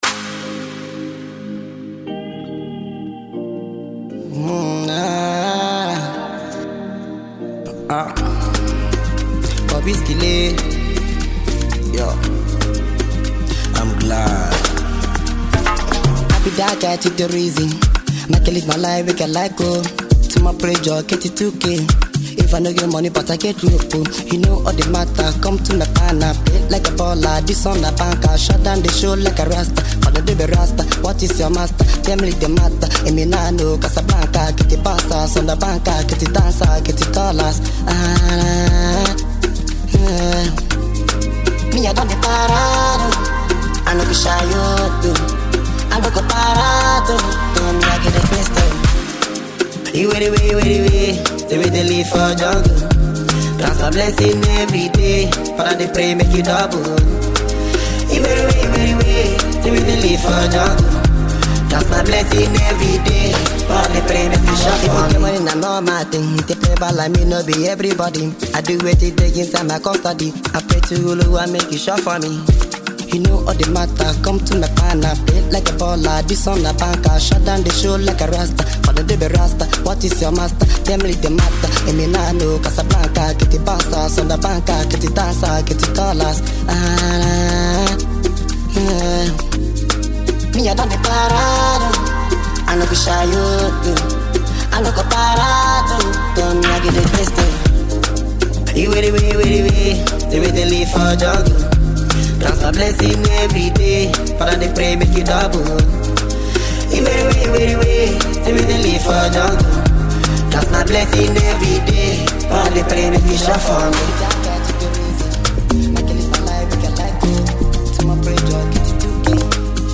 an upbeat tempo
If you’re a fan of Afrobeat or danceable, feel-good music